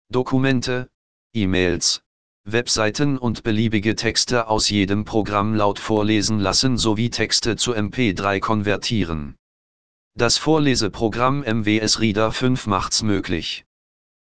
Diese Text to Speech Stimme wird mit MWS Reader ab Version 5.5 unter Windows 10 Deutsch automatisch freigeschaltet